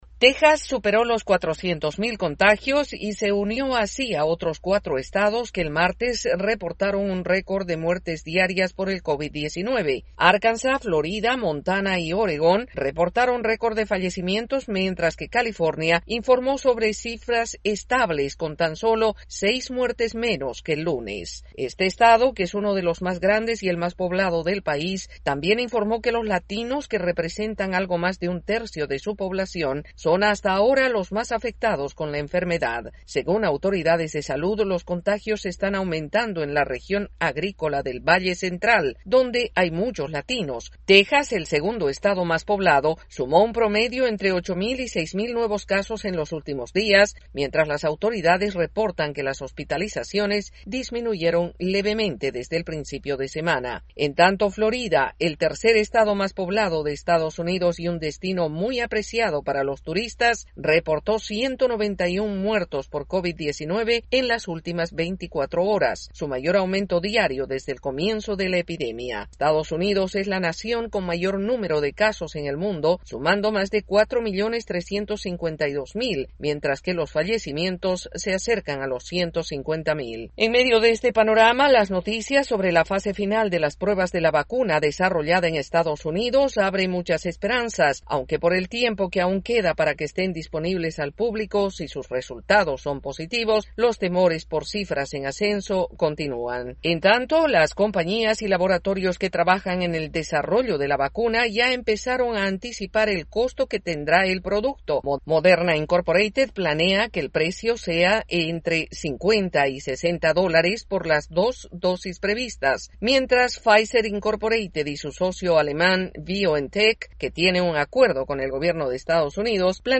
Cuatro Estados en Estados Unidos superan los 400 mil contagios con COVID-19 mientras las pruebas para las vacunas avanzan y se empieza a mencionar su posible precio. El informe desde la Voz de América en Washington DC